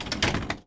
R - Foley 64.wav